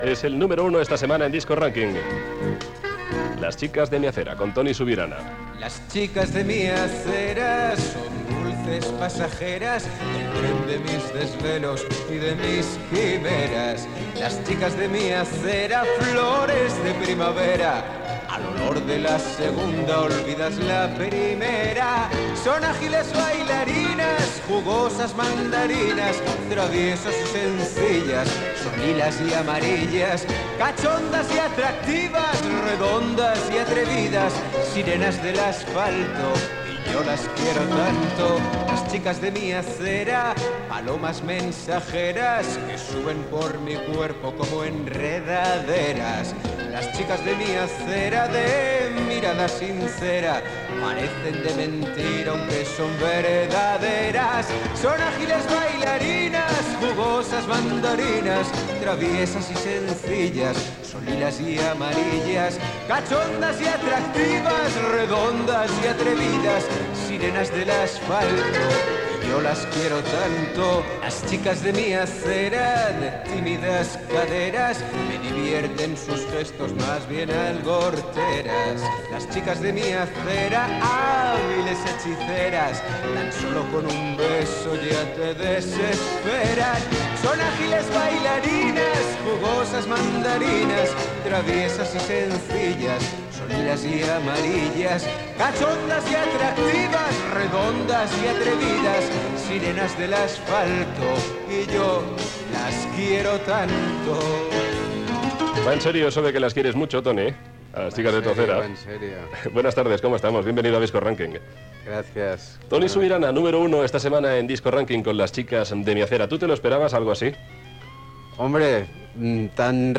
Entrevista
Gènere radiofònic Musical